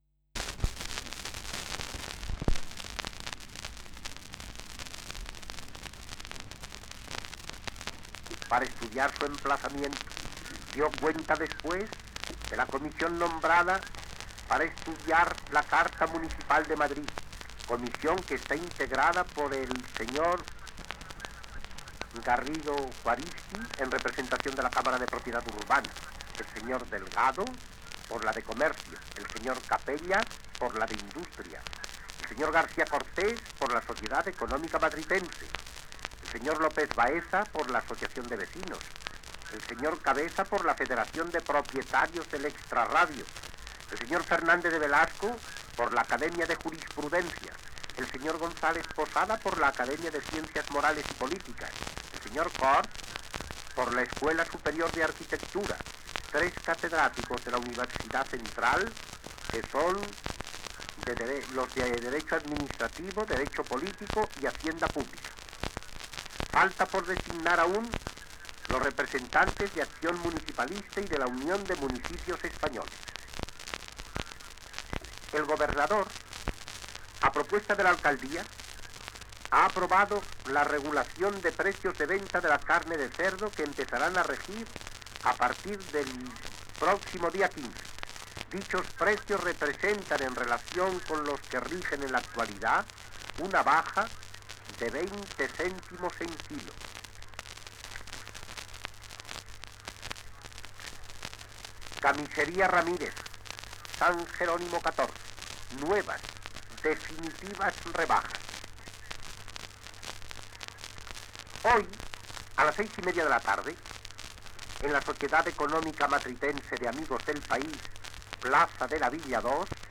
En la col·lecció que ens ha arribat, la digitalització es va fer amb un tocadiscos que permetia graduar-ne la velocitat de reproducció.
Es tracta del noticiari “La Palabra” d’Unión Radio Madrid, que havia absorbit Radio Barcelona el 1930. Entre d’altres informacions i publicitat, dóna notícia del rescat dels supervivents del desastre del dirigible nord-americà MACON, produït per una tempesta el 12 de febrer de 1935 a San Francisco.